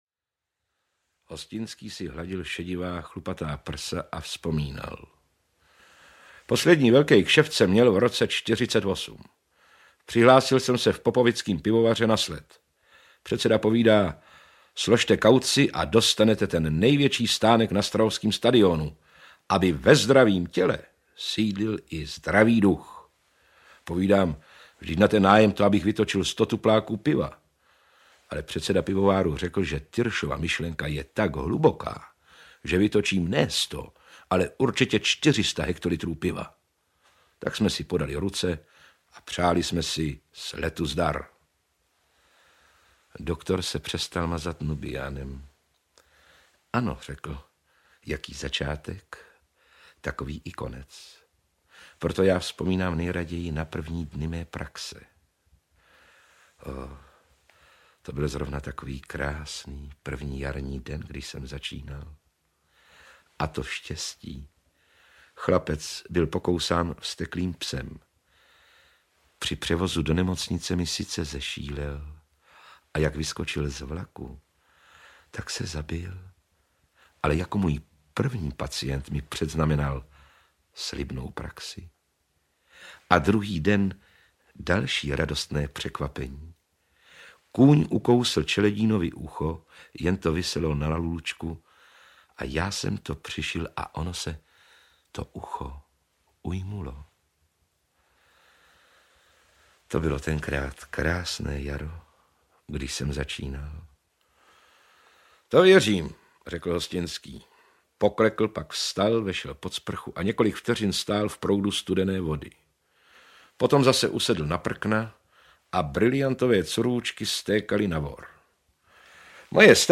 Výběr z díla legendárního spisovatele audiokniha
Ukázka z knihy